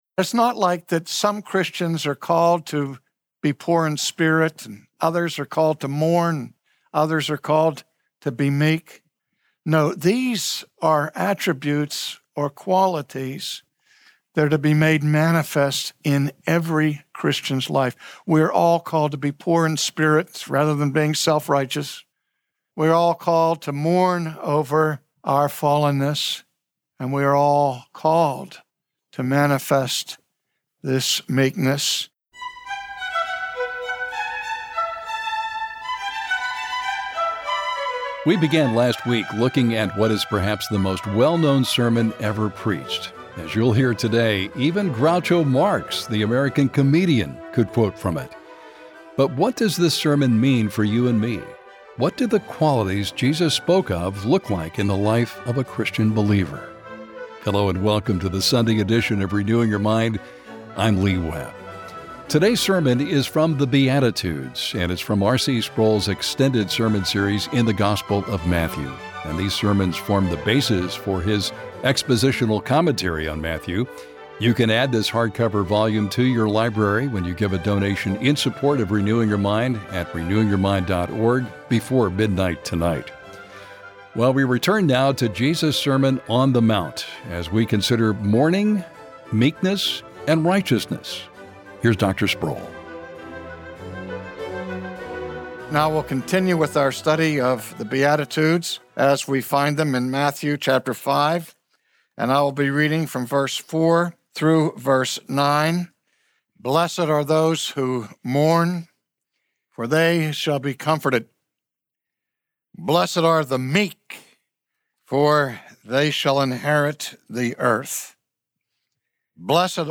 Yet Jesus singled out the mournful people of God as those who will enjoy His comfort. From his sermon series in the gospel of Matthew, today R.C. Sproul to survey the surprising blessings of the Beatitudes.